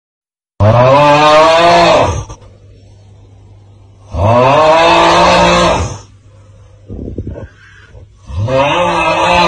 Snoring meme original 🗣 sound effects free download